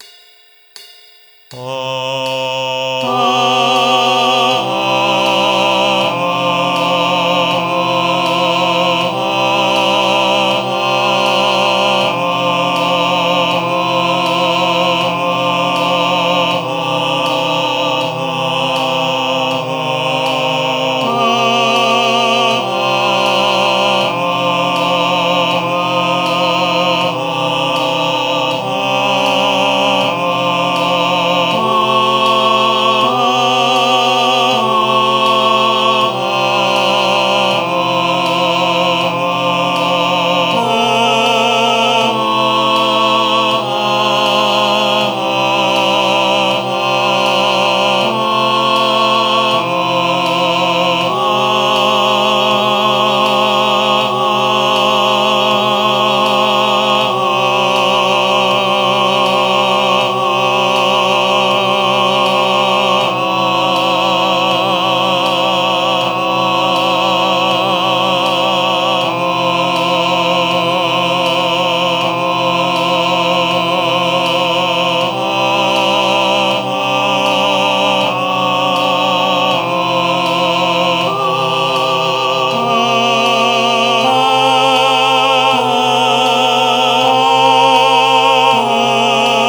I’ve included files with a drone of the key center.
Soprano and Tenor Only with Drone
MP3 with Soprano and Tenor only with Cymbal